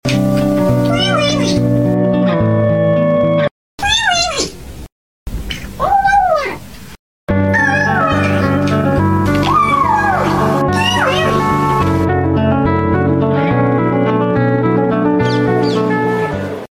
Zzzzzz sound effects free download